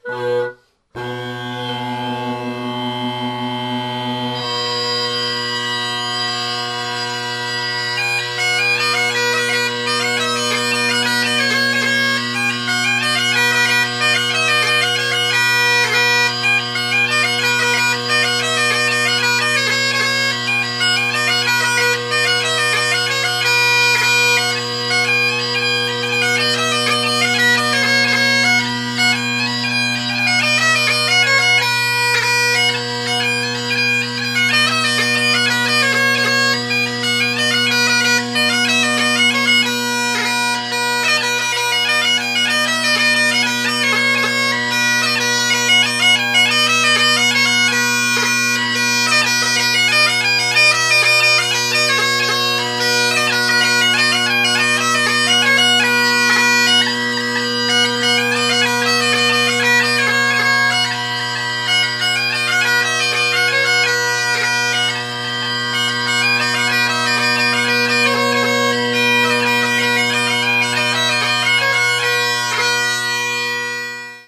Great Highland Bagpipe Solo
Also, no one else was home so I got to play in a bigger room.